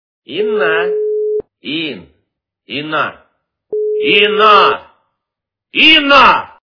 » Звуки » Именные звонки » Именной звонок для Инны - Инна, Ин, Инна, Инна, Инна
При прослушивании Именной звонок для Инны - Инна, Ин, Инна, Инна, Инна качество понижено и присутствуют гудки.